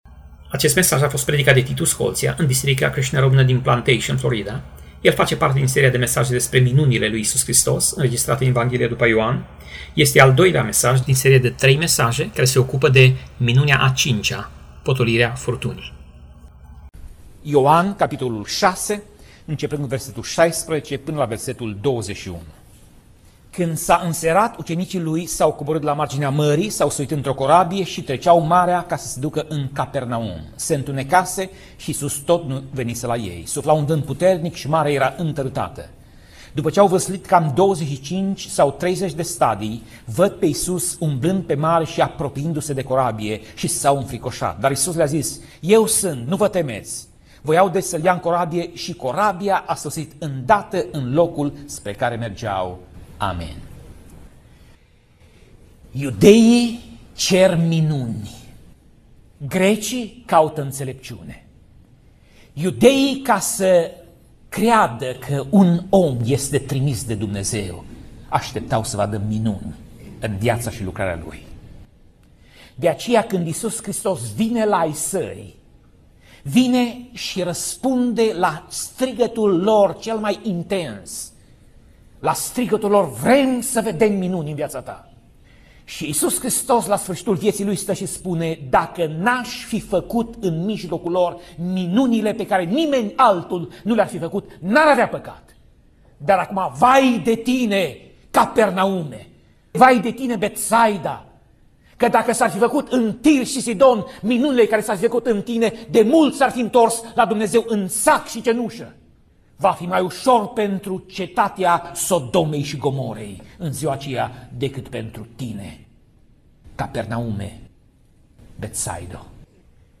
Pasaj Biblie: Ioan 6:16 - Ioan 6:21 Tip Mesaj: Predica